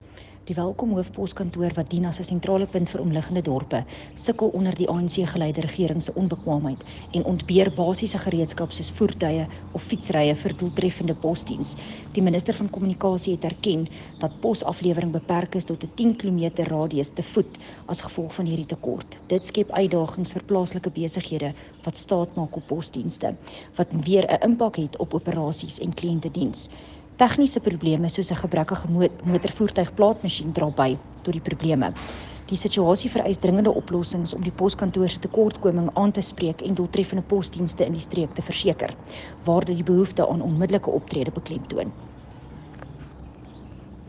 Afrikaans soundbites by Cllr René Steyn and